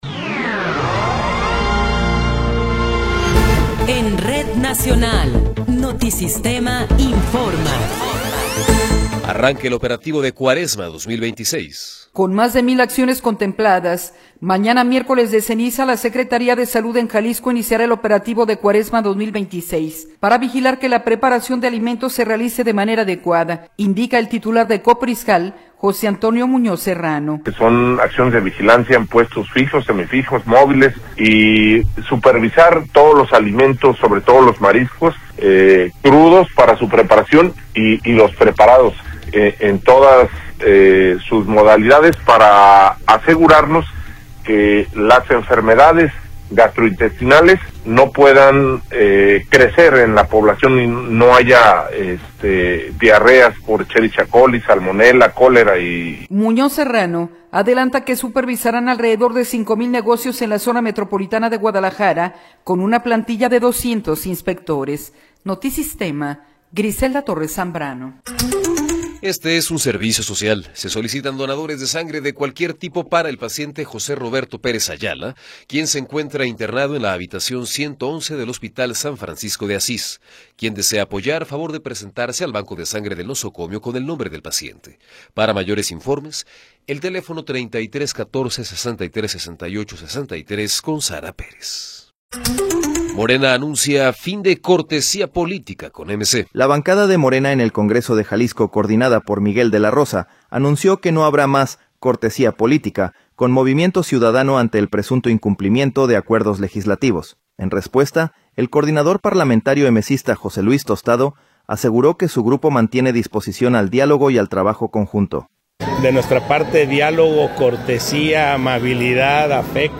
Noticiero 11 hrs. – 17 de Febrero de 2026
Resumen informativo Notisistema, la mejor y más completa información cada hora en la hora.